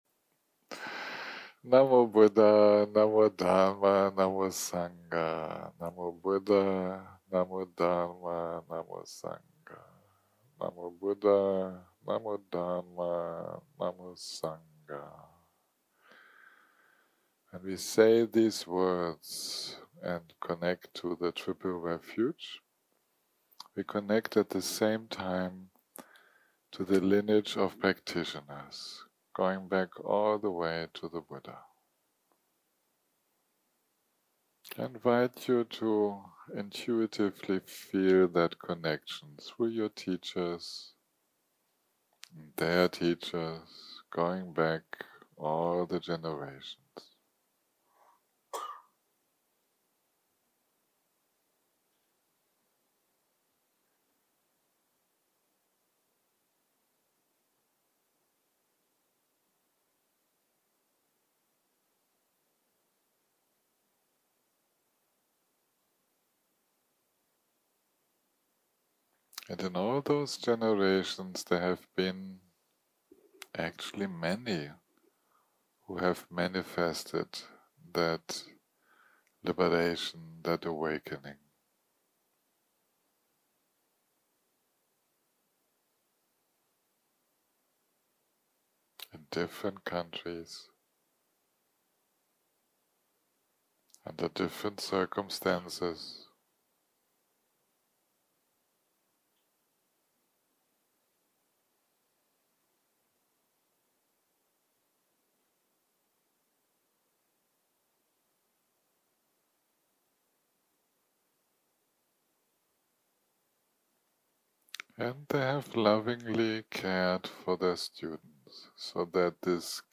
יום 5 - הקלטה 19 - בוקר - מדיטציה מונחית - The unity of wisdom and compassion - part 1 0:00 0:00 סוג ההקלטה: סוג ההקלטה: מדיטציה מונחית שפת ההקלטה: שפת ההקלטה: אנגלית